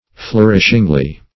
flourishingly - definition of flourishingly - synonyms, pronunciation, spelling from Free Dictionary Search Result for " flourishingly" : The Collaborative International Dictionary of English v.0.48: Flourishingly \Flour"ish*ing*ly\, adv. In a flourishing manner; ostentatiously.
flourishingly.mp3